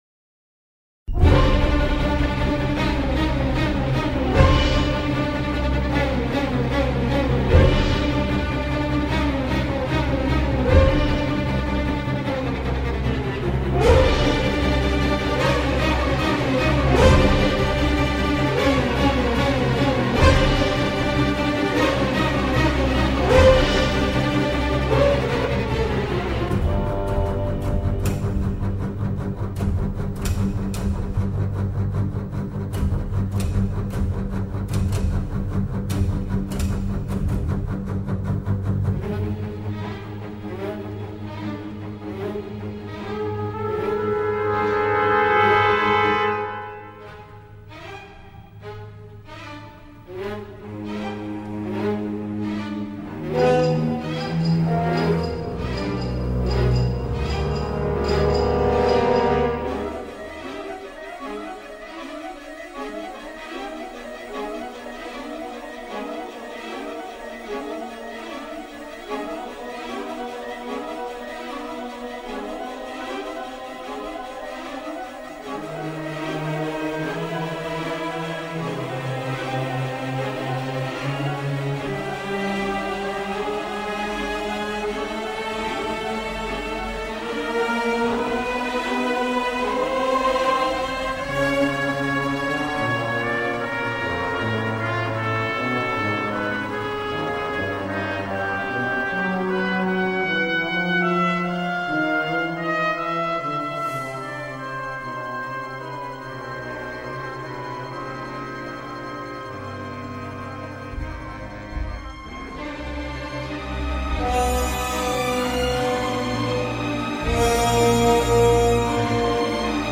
chœurs inarticulés